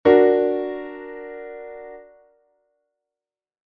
Que tipo de acorde estás a escoitar?
aumentado1.mp3